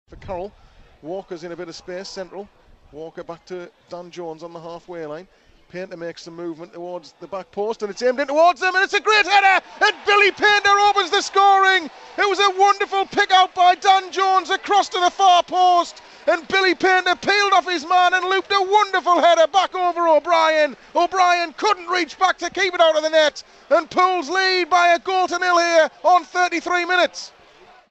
Listen to Billy Paynter's decisive goal in Pools' win at Dagenham as it sounded live on Pools PlayerHD.